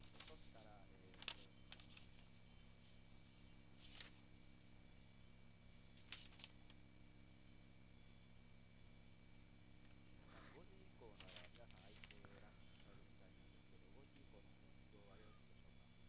osa 0915 (Monaural AU Sound Data)